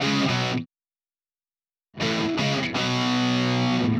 Power Pop Punk Guitar Intro 02.wav